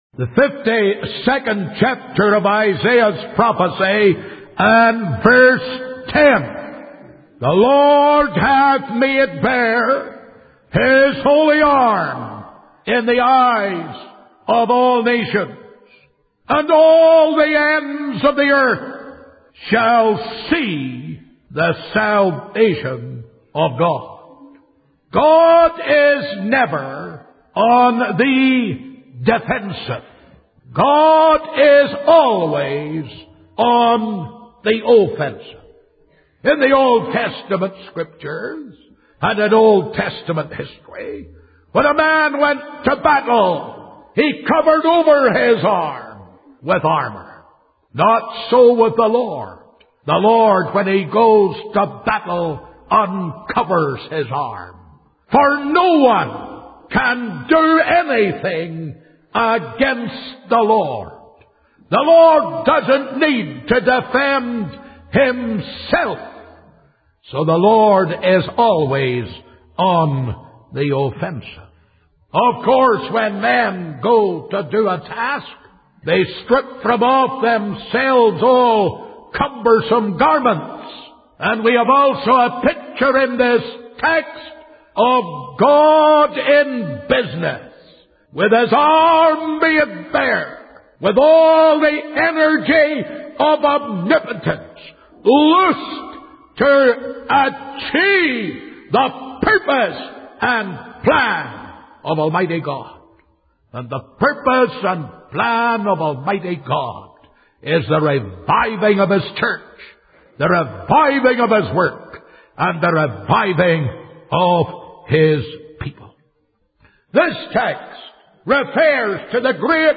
In this sermon, the preacher emphasizes the importance of being filled with the Spirit of God and immersing oneself in God's word before going out to witness.